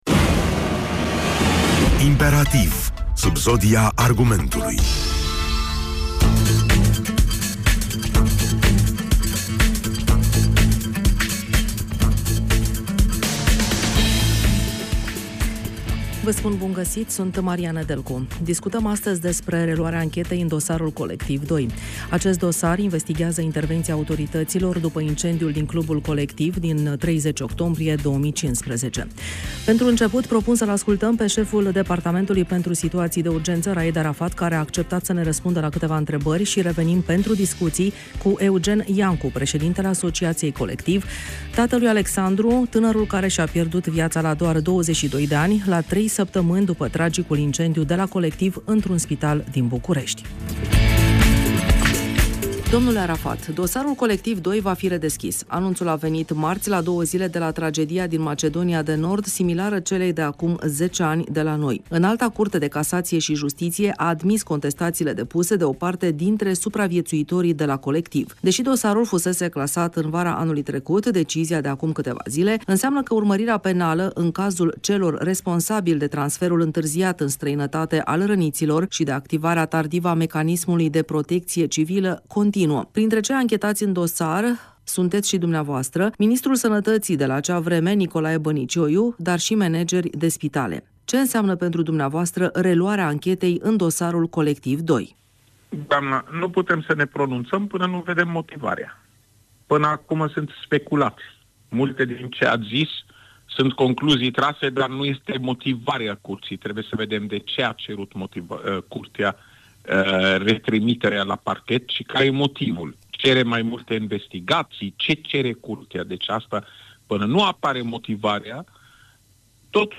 Emisiunea a fost transmisă pe toate frecvențele Radio Iași precum si pe pagina noastră de Facebook , de la ora 14.00.